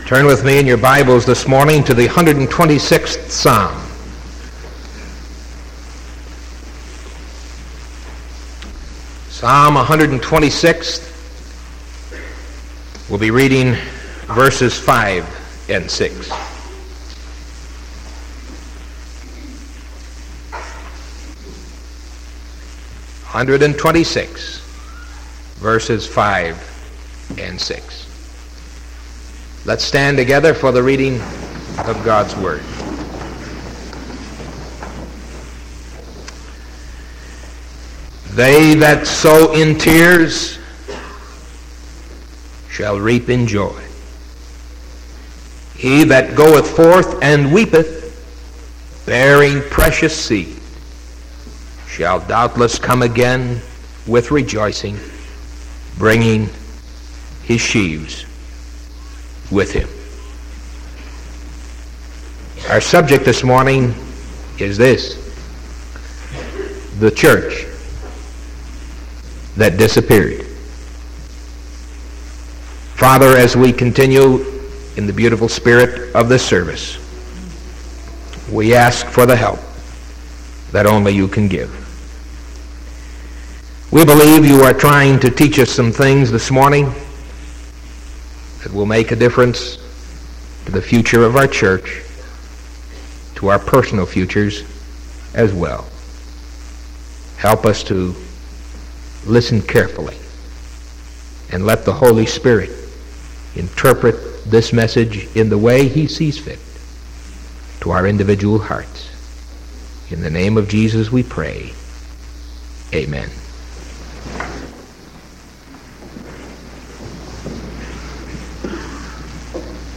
Sermon October 20th 1974 AM